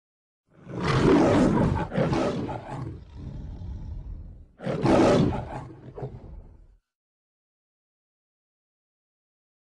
دانلود صدای نعره یا غرش شیر 3 از ساعد نیوز با لینک مستقیم و کیفیت بالا
جلوه های صوتی